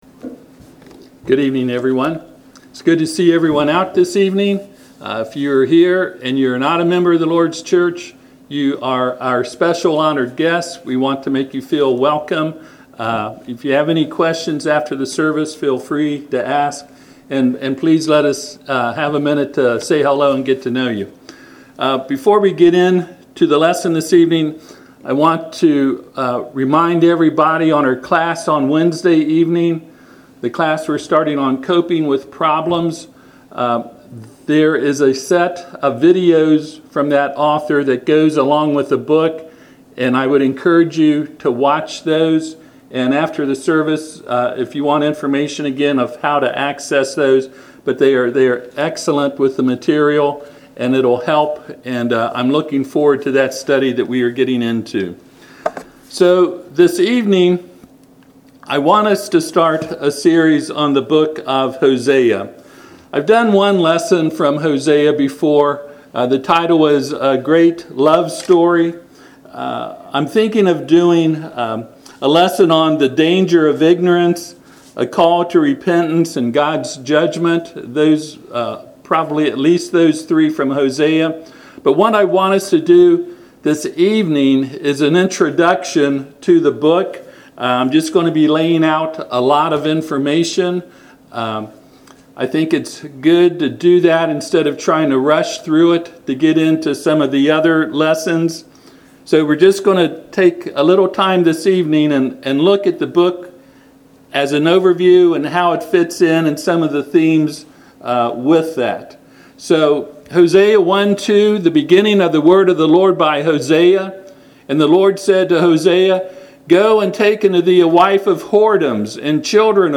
Passage: Hosea 1:1-2 Service Type: Sunday PM